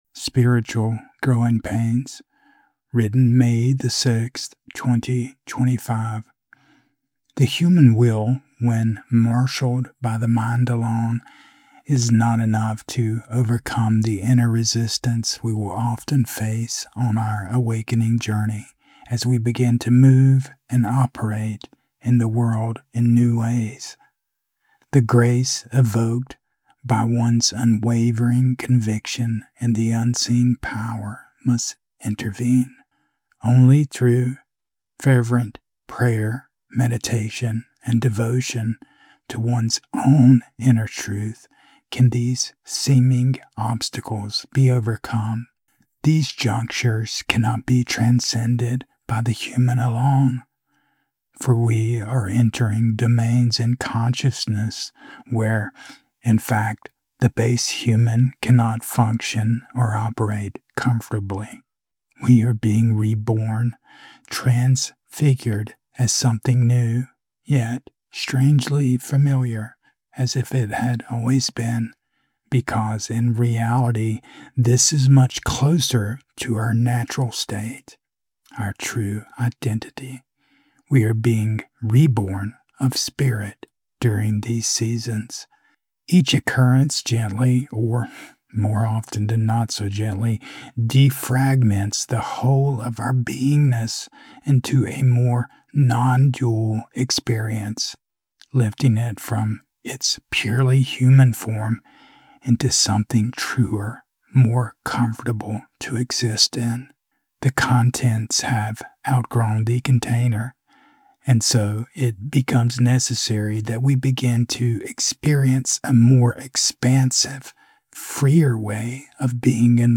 Spoken Audio